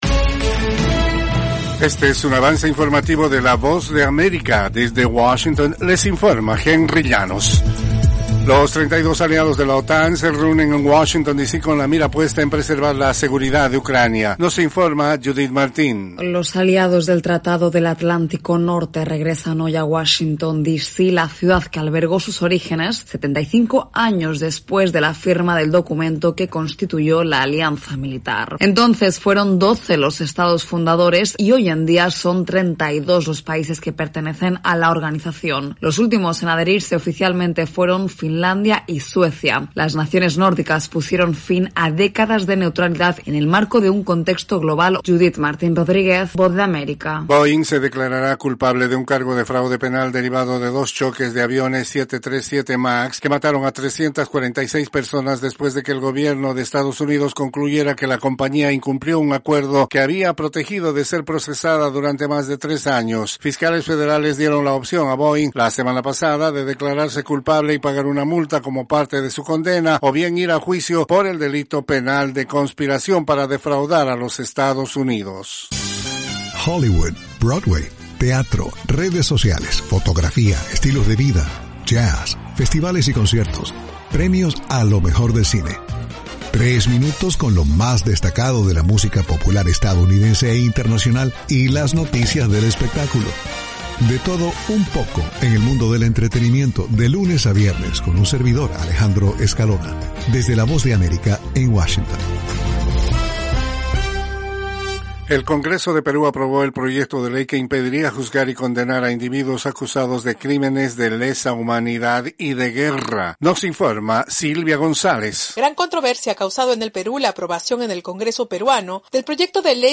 Cápsula informativa de tres minutos con el acontecer noticioso de Estados Unidos y el mundo.